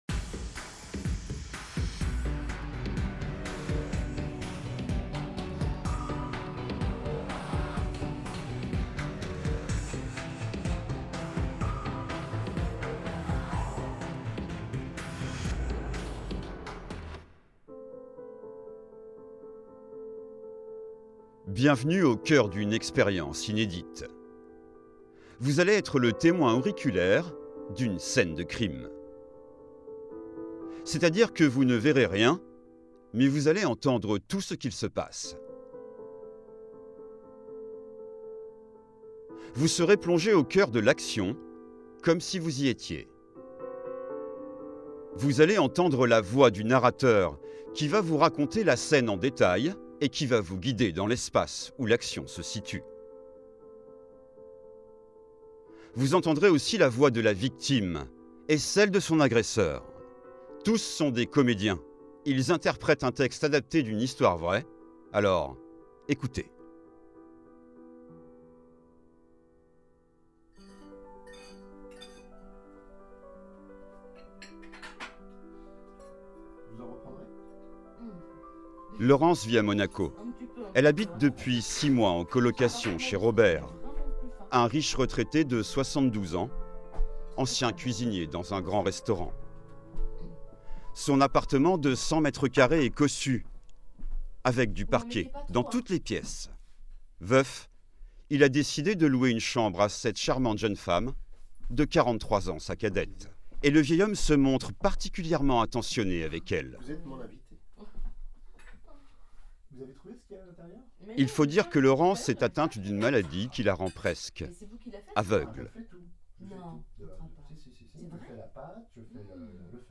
Binaural consulting
Equipped with its Neumann KU100 head (possibility of having 2), she manages the sound recording of your project but also the editing and mixing (with the possibility of using synthesis tools), for the best audio rendering of your listeners.